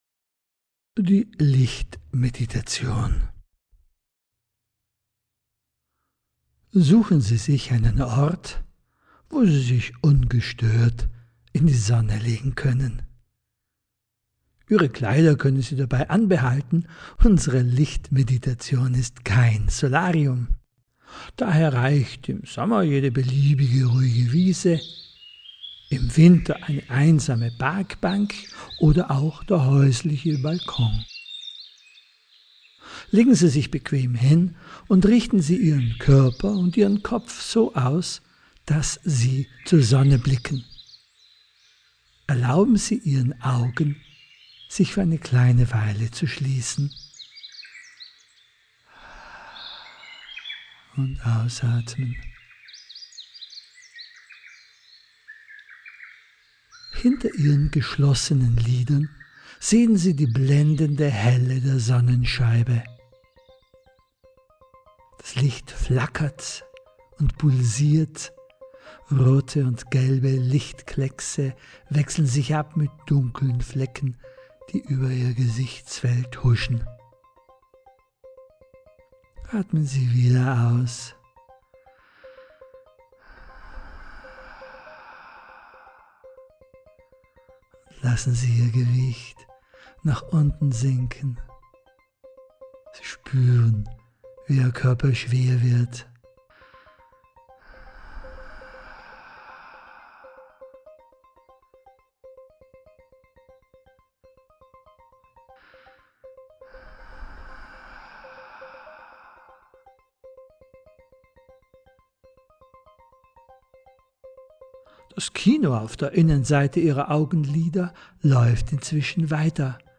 Lichtmeditation: Stimme, Geräusche und Musik, 6 Minuten, komprimierte mp3-Version, 6 MB
Relax04-Lichtmeditation-Musik-komprimiert.mp3